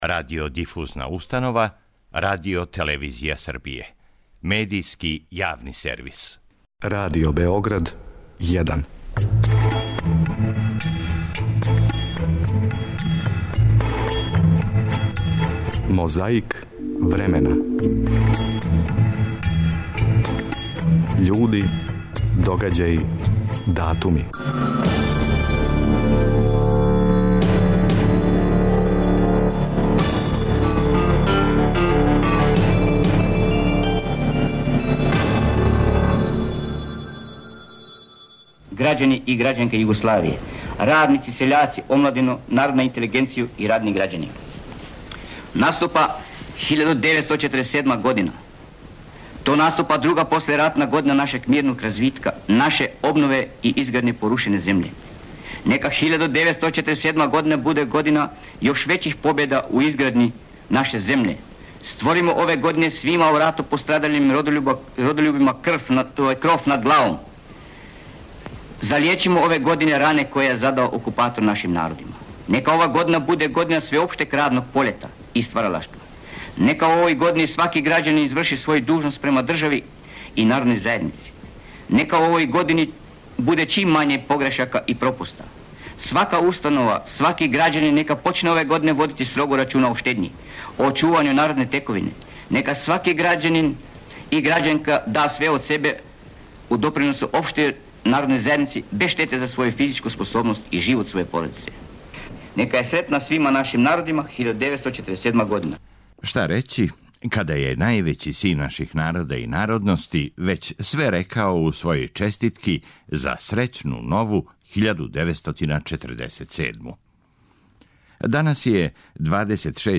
После избора, на ред је дошла конференција за новинаре. 29. децембар 2003.
Подсећа на прошлост (културну, историјску, политичку, спортску и сваку другу) уз помоћ материјала из Тонског архива, Документације и библиотеке Радио Београда.